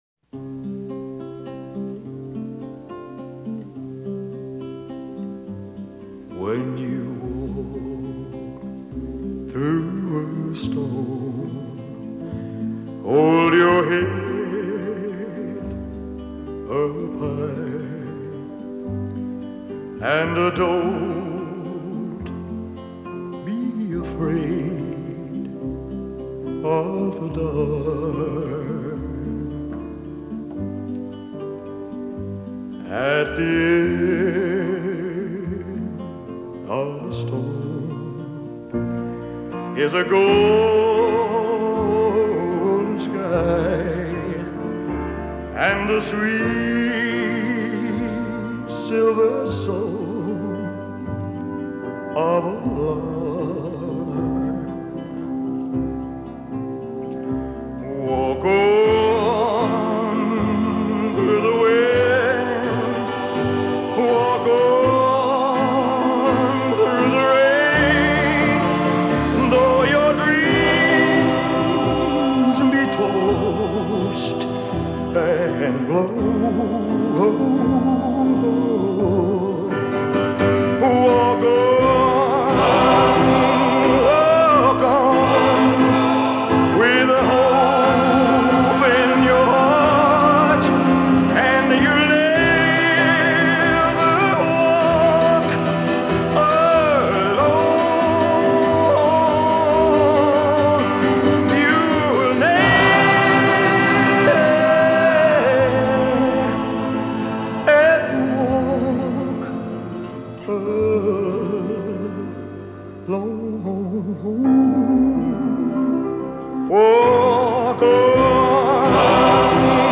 soft rock سافت راک